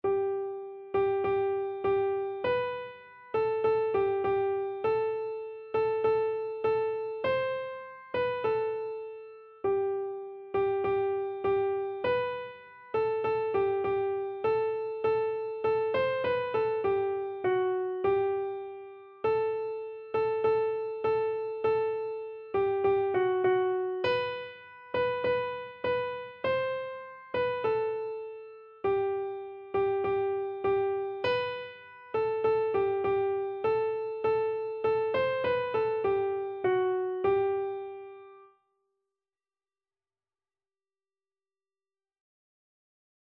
Christian
Free Sheet music for Keyboard (Melody and Chords)
4/4 (View more 4/4 Music)
Classical (View more Classical Keyboard Music)